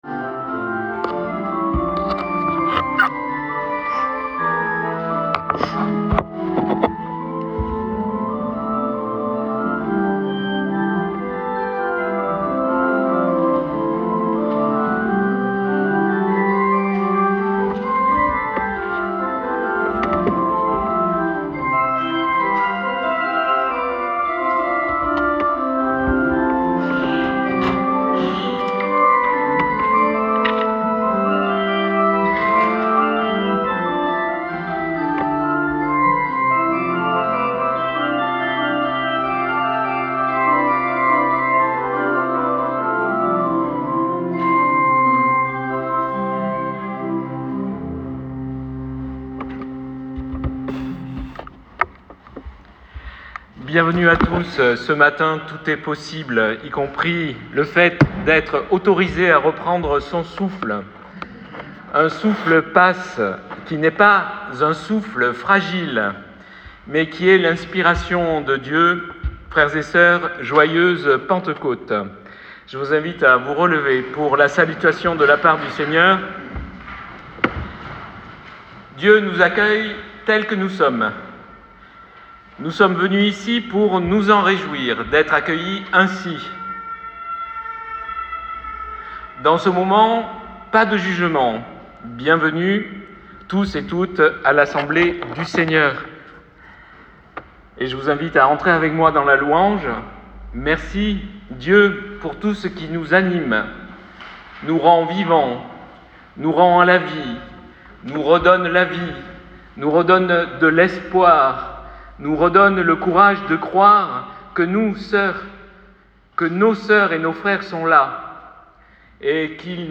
La prédication du 8 juin 2025.pdf (38.17 Ko) Culte du 8 juin, prédication incluse.mp3 (91.71 Mo)